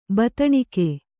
♪ bataṇike